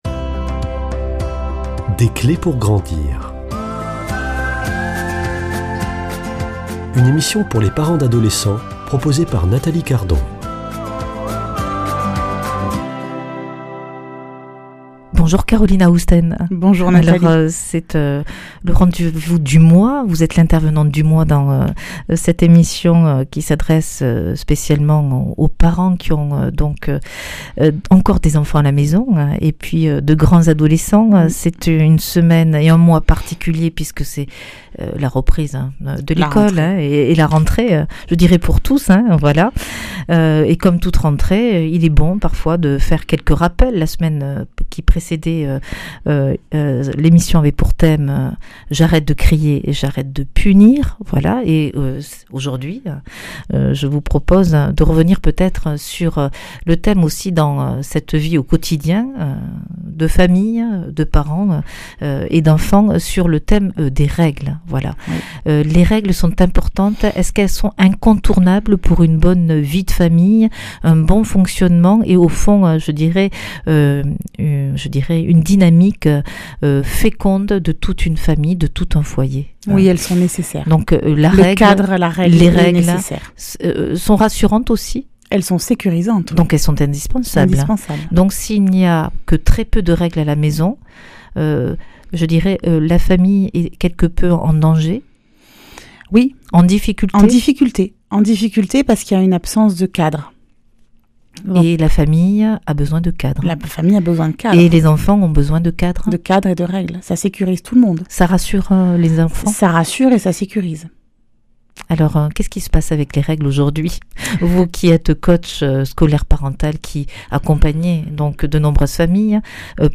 [ Rediffusion ] Autoritarisme